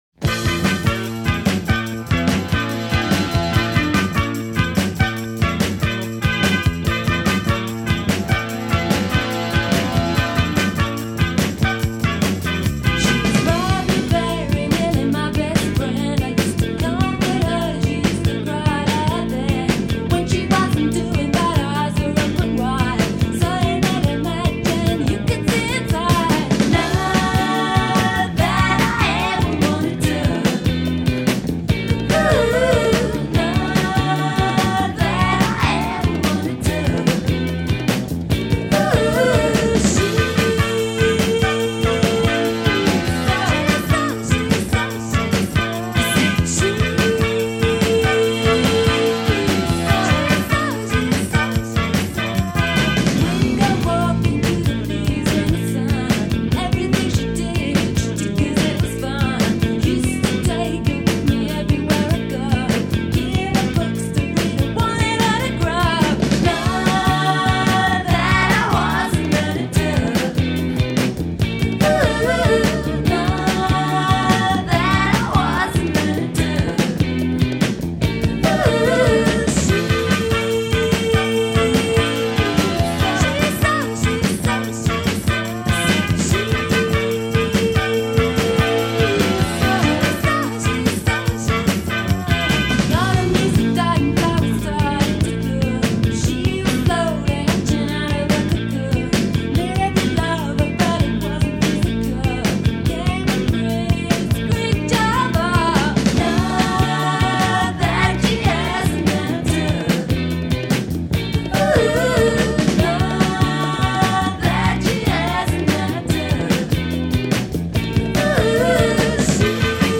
catchy
++ What bands influenced your breezy and POP! sound?